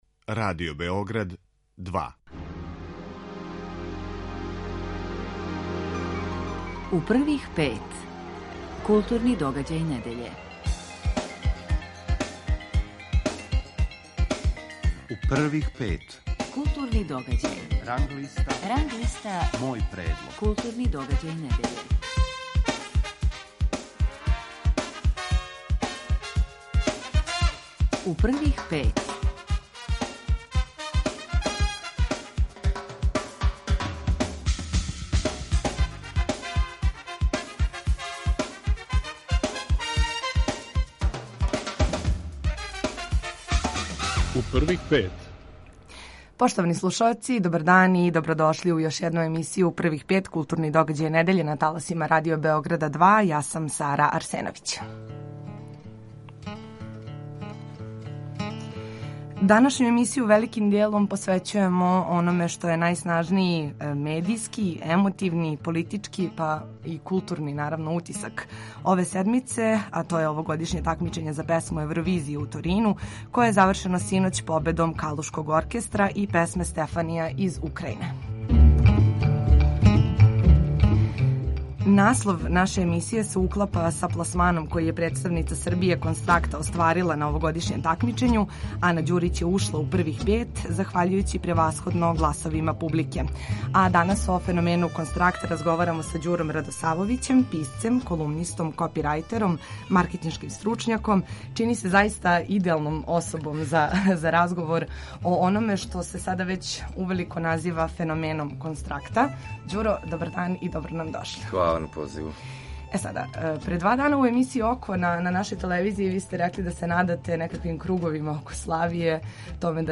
Гост емисије
Белдокса, манифестацију „Молијерови дани", концерт састава „Музикон" и „VooDoo piano duо" у Коларчевој задужбини, манифестацију „Музеји за 10", а очекује нас и укључење из Торина, где је одржано тамичење за Песму Евровизије.